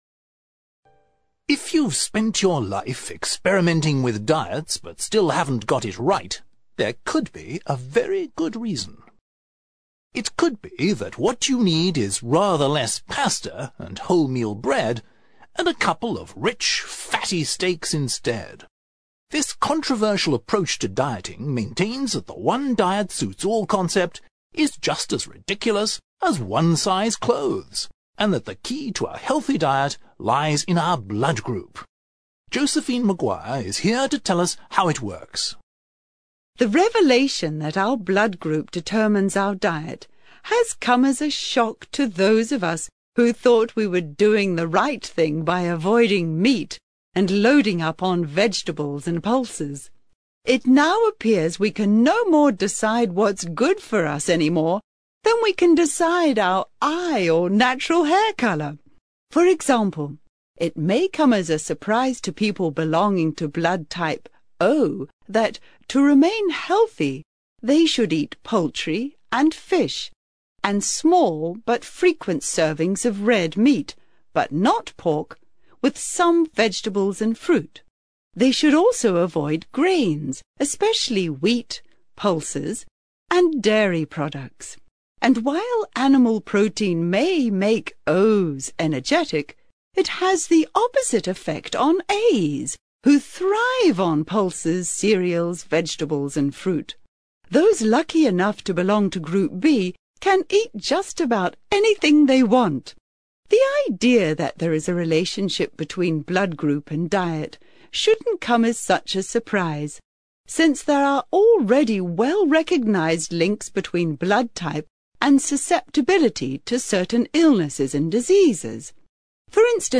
ACTIVITY 72: You will hear a talk about the connection between blood group and diet.